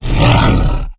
c_sibtiger_atk1.wav